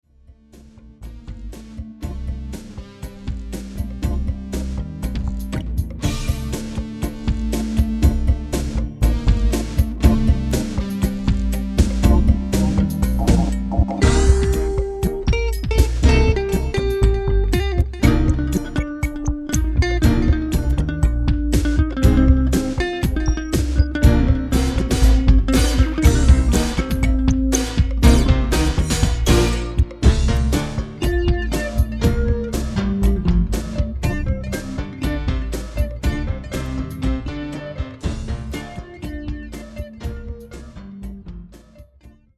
Good pop style, you want to dance?